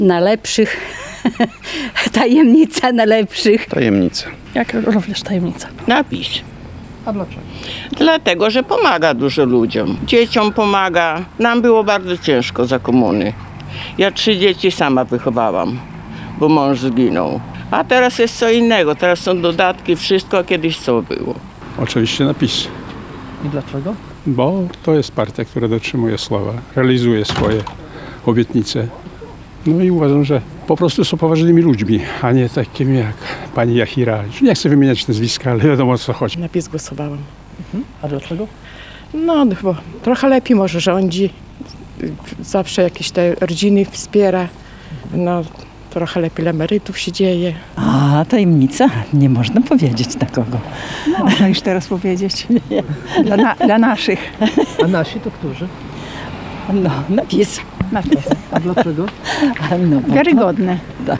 Prawo i Sprawiedliwość wymieniali zwykle suwalczanie pytani o to, na kogo głosowali? Tradycyjnie już, z kamerą i mikrofonem, monitorowaliśmy i relacjonowaliśmy przebieg głosowania.  Jednocześnie pytaliśmy głosujących o ich wybór.